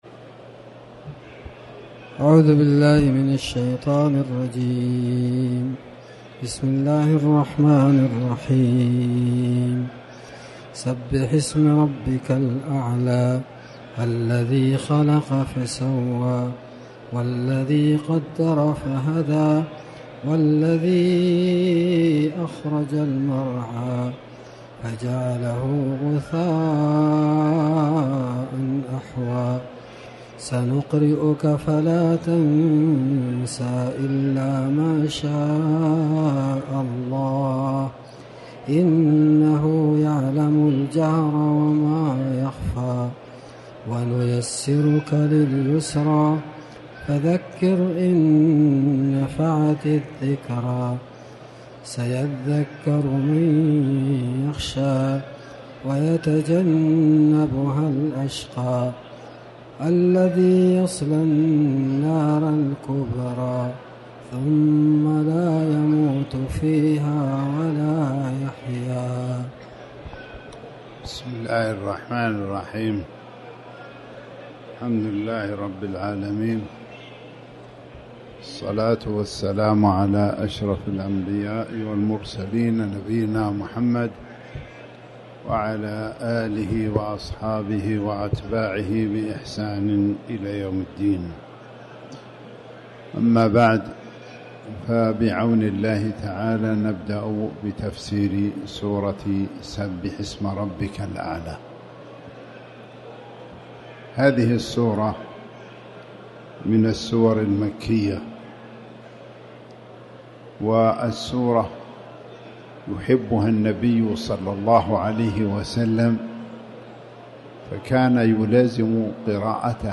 تاريخ النشر ١٤ شوال ١٤٤٠ هـ المكان: المسجد الحرام الشيخ